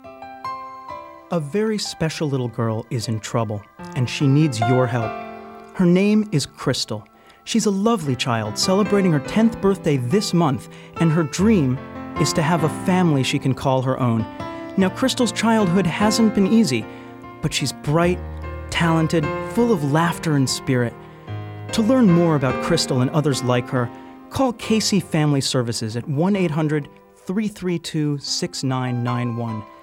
Engaging, Authentic, Articulate Storyteller
General (Standard) American, Brooklyn
Middle Aged